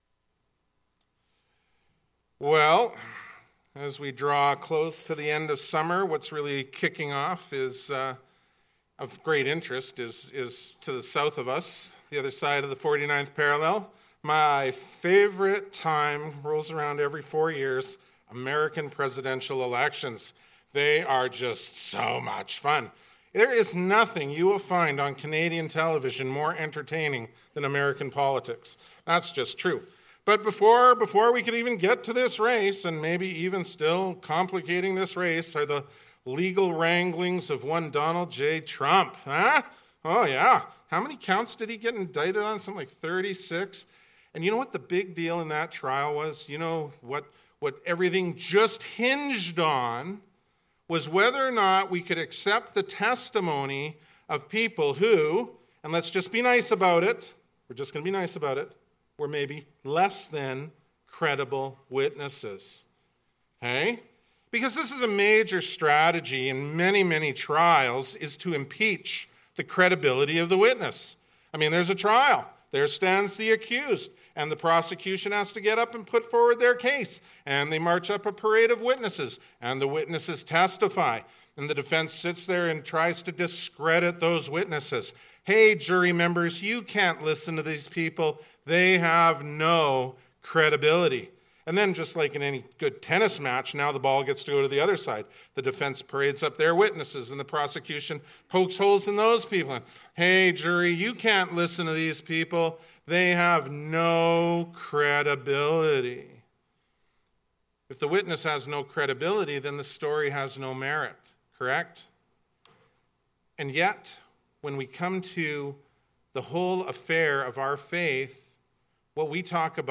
2024 How to Be a Credible Witness Preacher